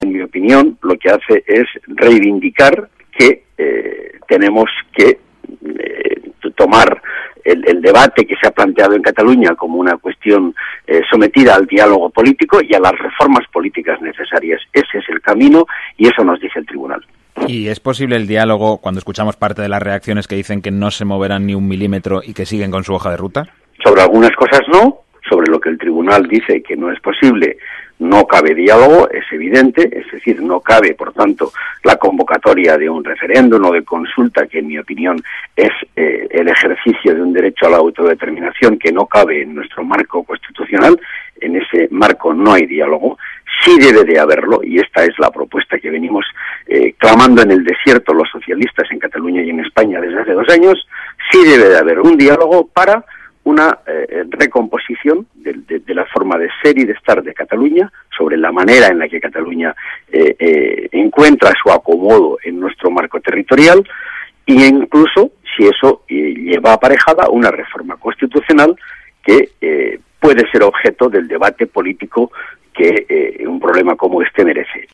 Fragmento de la entrevista a Ramón Jáuregui en RNE en el que valora la sentencia del Tribunal Constitucional sobre la declaración del parlamento catalán en relación con su soberanía 26/03/2014